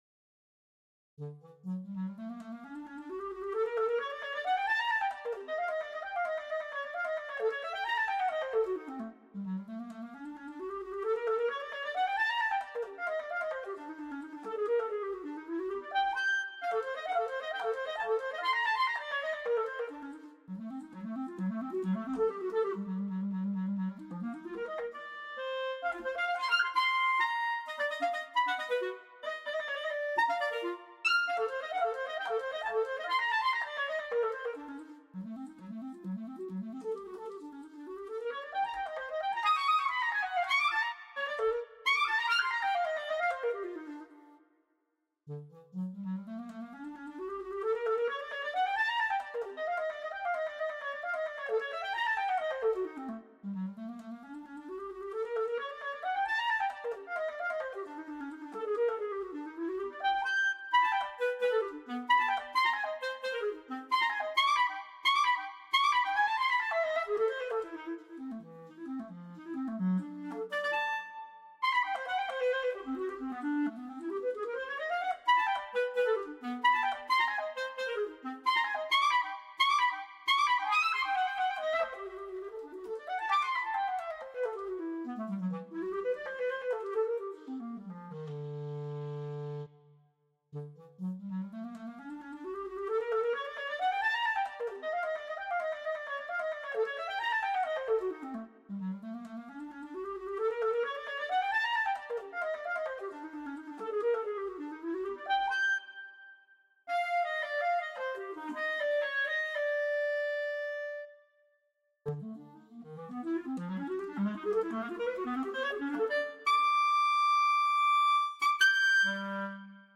for solo clarinet